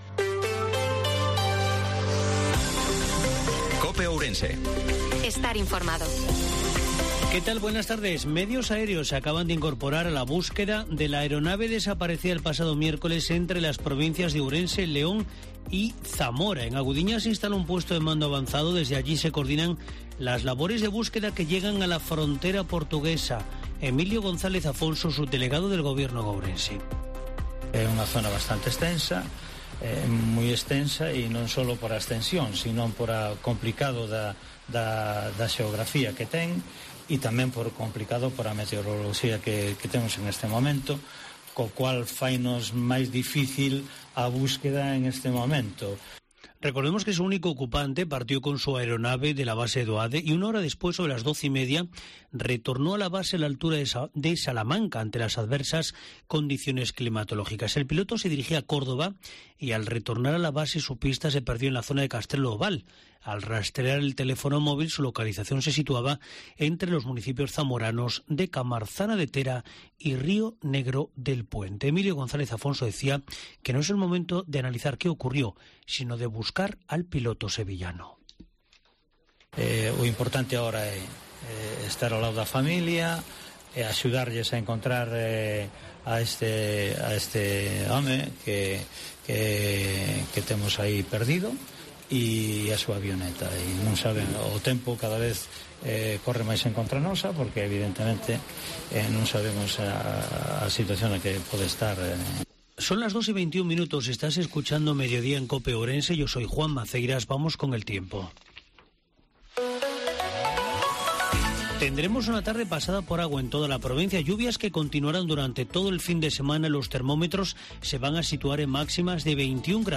INFORMATIVO MEDIODIA COPE OURENSE-21/10/2022